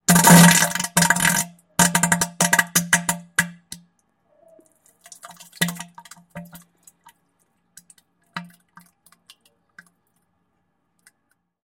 Звуки поноса
Звуки диареи и испражнений человека: звук поноса из заднего прохода (дело серьезное)